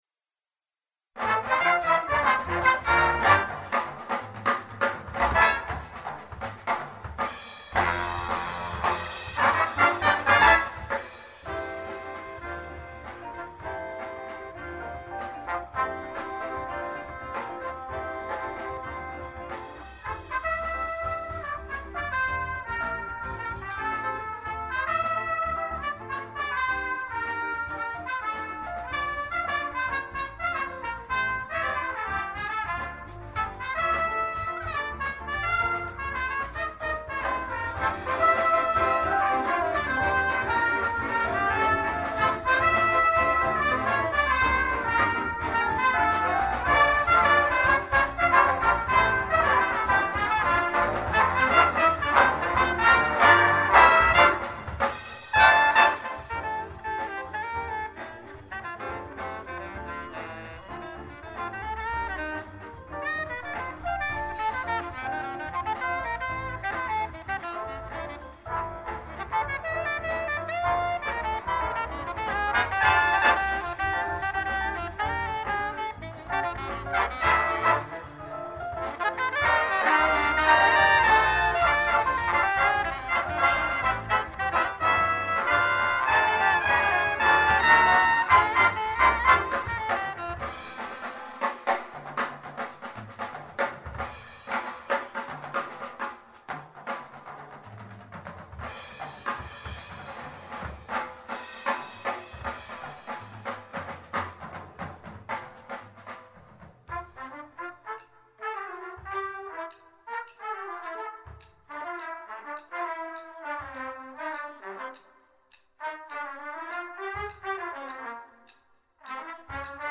lively latin original